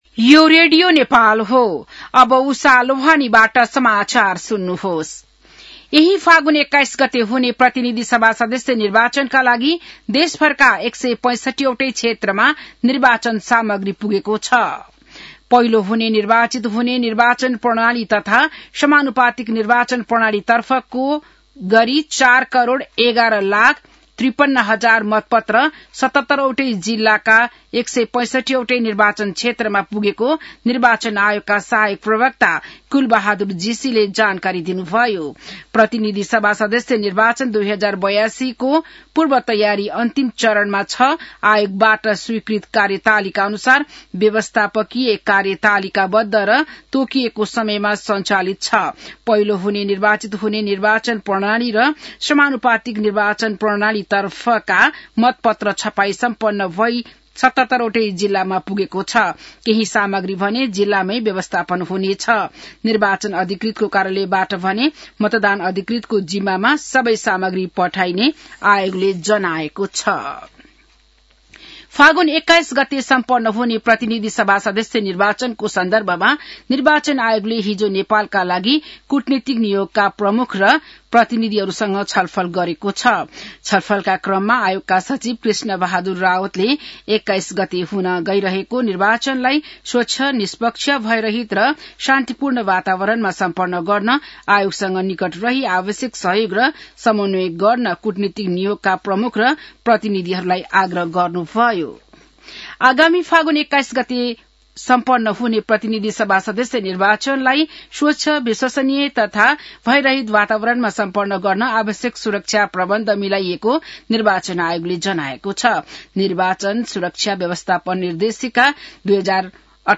बिहान १० बजेको नेपाली समाचार : १५ फागुन , २०८२